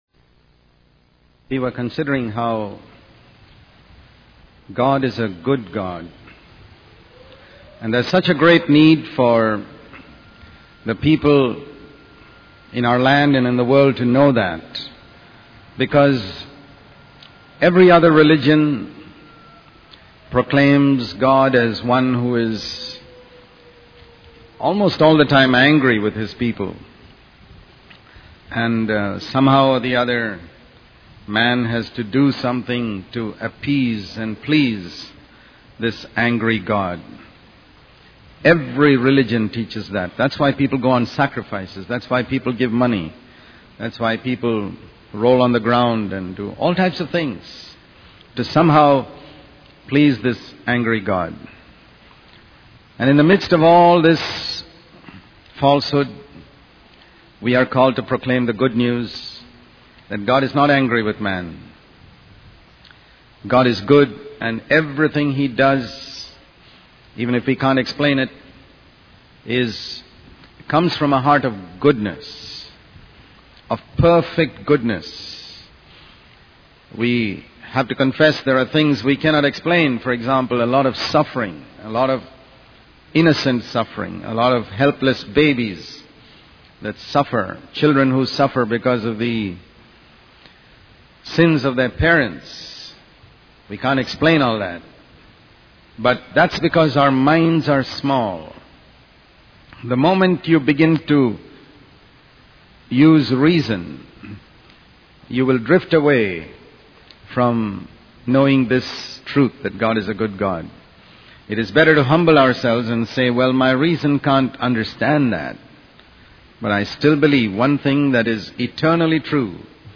In this sermon, the speaker emphasizes the importance of understanding the motives behind our actions. He highlights that even acts of fasting, prayer, and helping the poor can be seen as worthless in God's eyes if they lack genuine love.